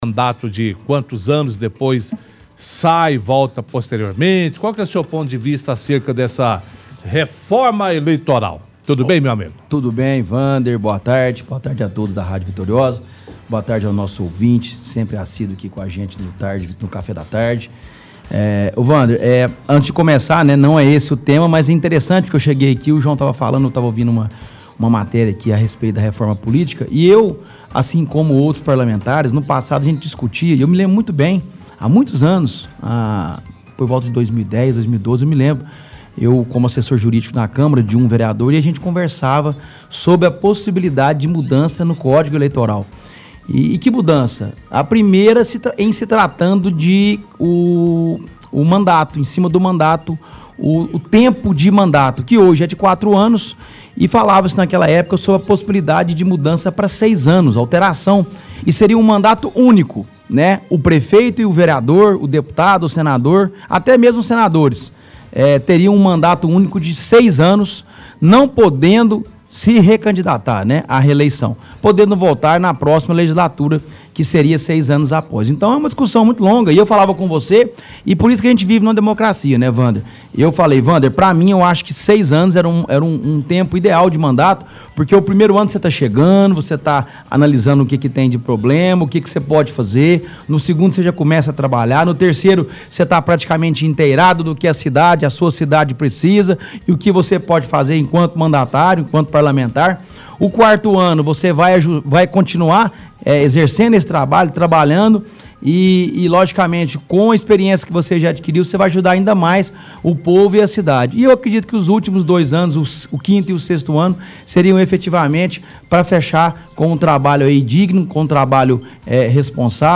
– Debate com o apresentador sobre projeto de lei do Governo para a regulamentação do serviço no Brasil.